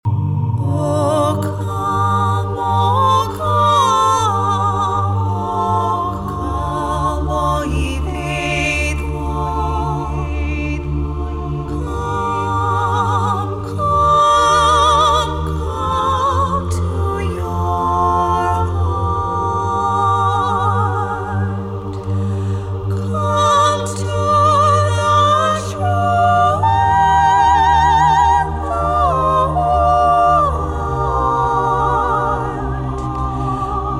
soaring vocals blend in beautiful harmonies